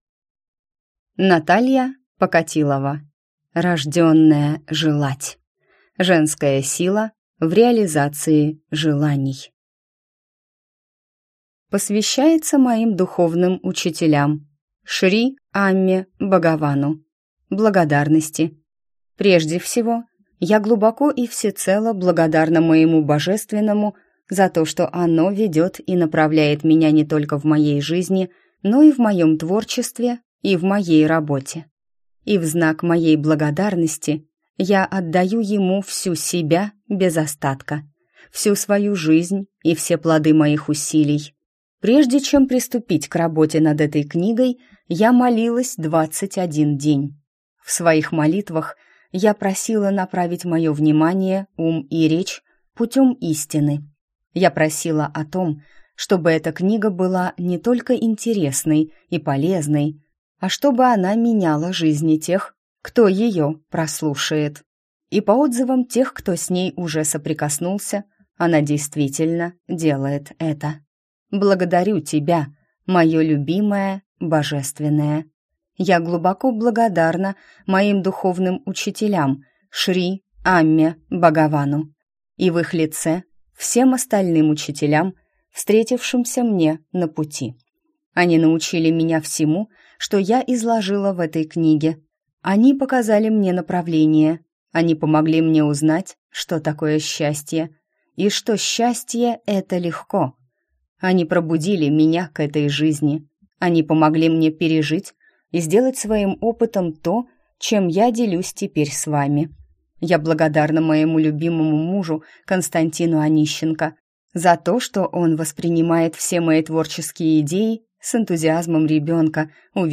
Аудиокнига Рожденная желать. Женская сила в реализации желаний | Библиотека аудиокниг
Прослушать и бесплатно скачать фрагмент аудиокниги